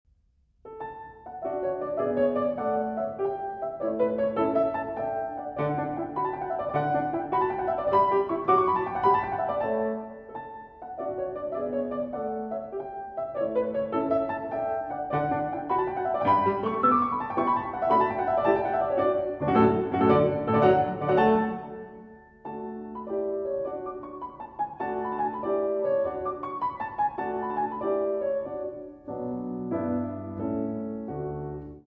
Mozart: Piano Sonata K. 311, III, measures 1 - 16Score